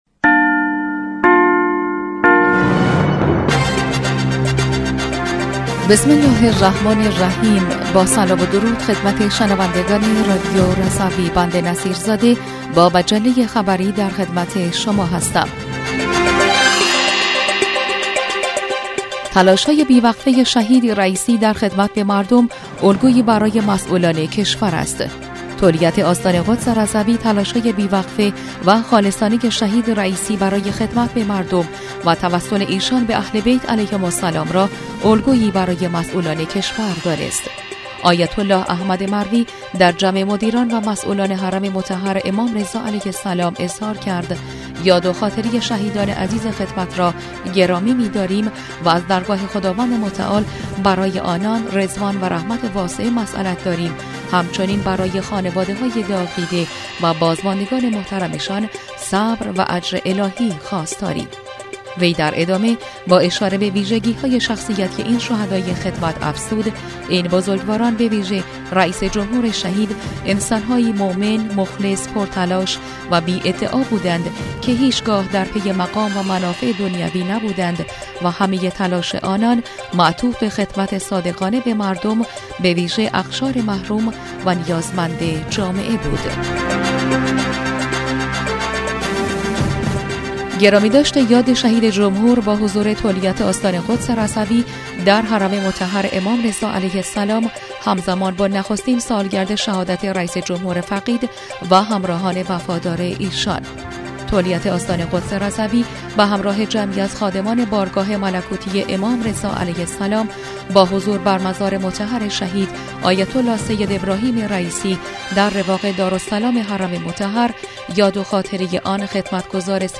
بسته خبری پنجشنبه ۱ خردادماه رادیو رضوی/